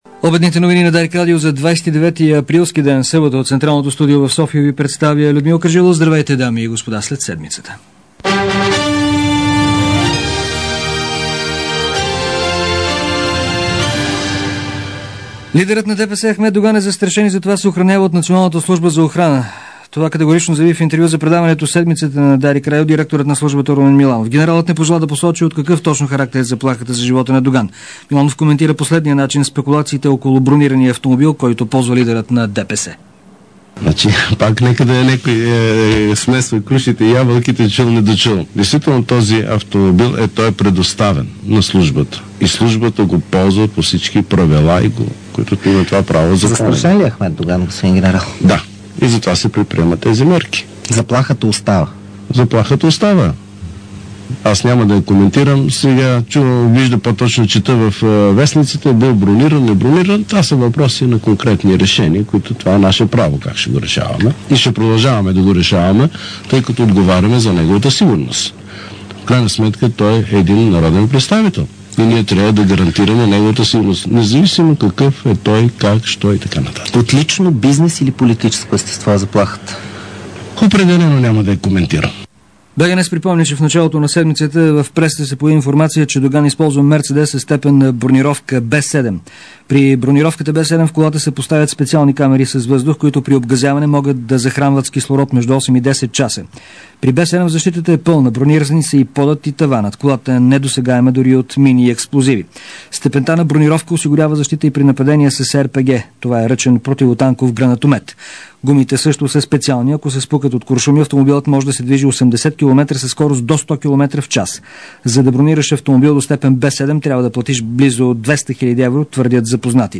DarikNews audio: Обедна информационна емисия 29.04.2006